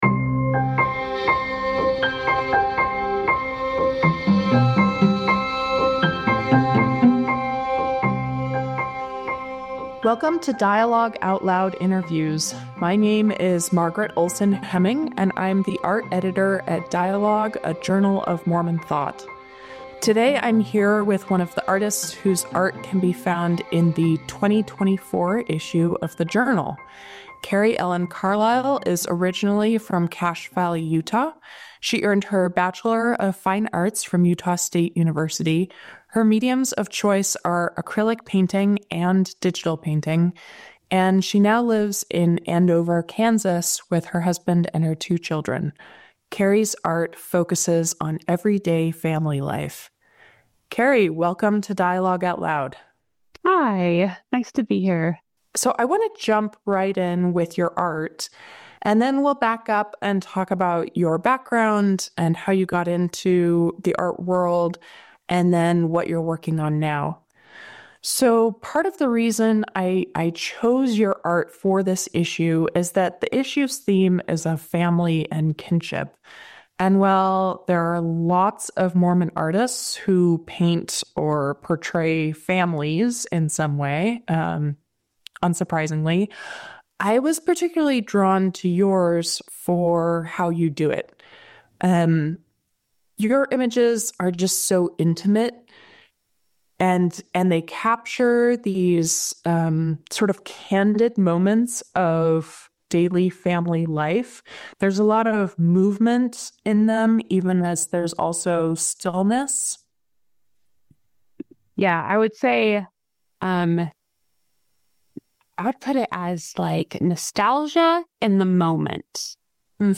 Painting the Familiar: A Conversation